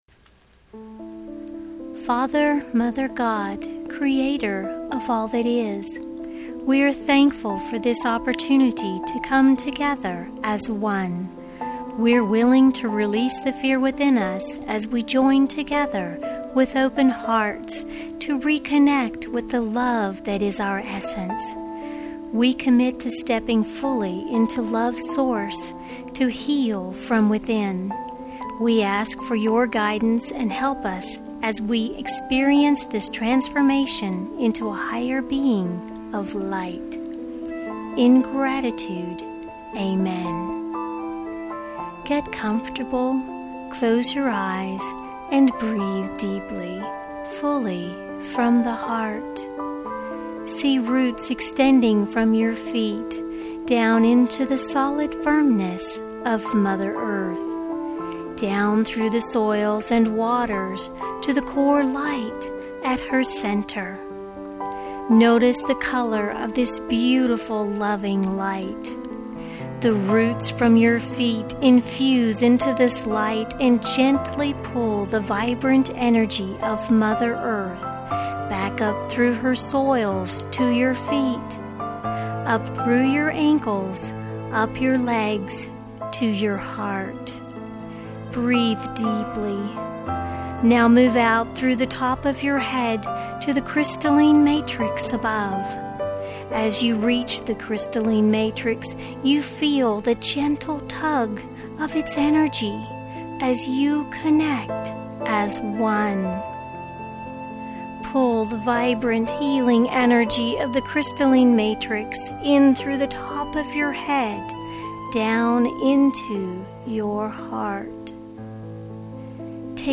Love_Meditation.mp3